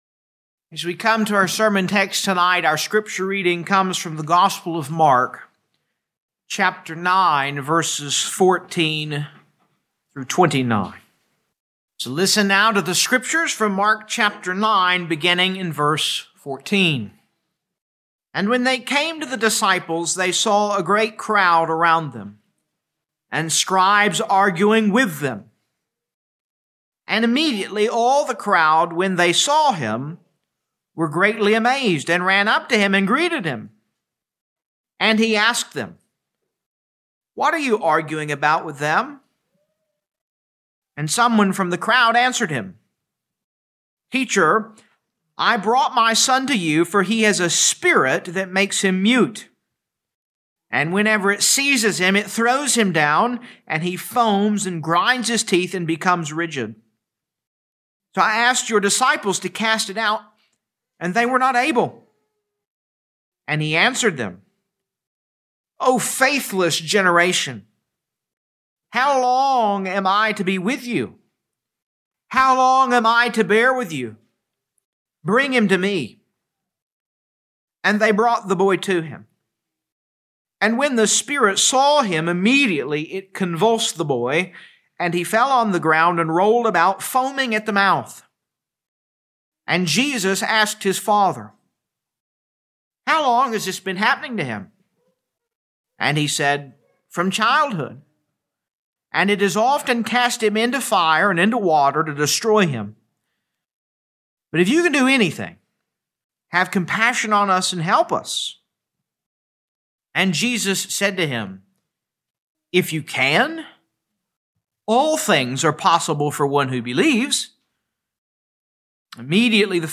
2026 Mark Evening Service Download